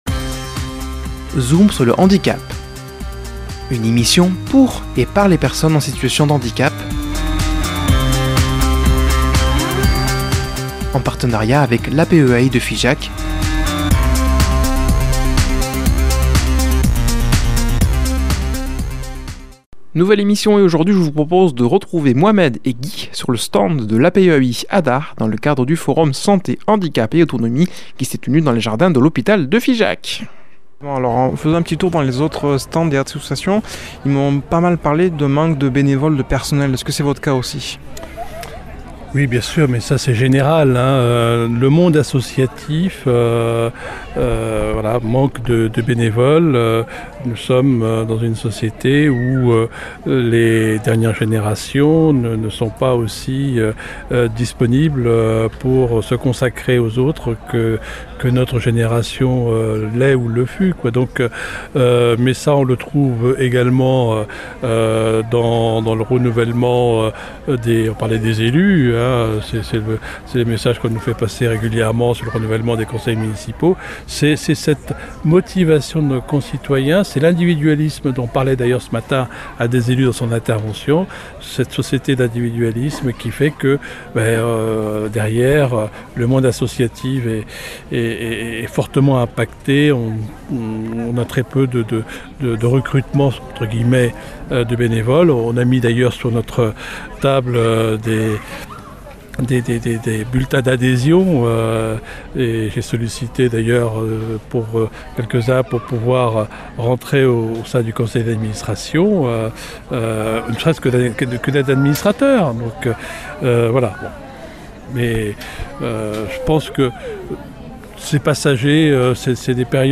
Un reportage en plusieurs parties tout au long de cette saison radiophonique.